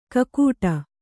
♪ kakūṭa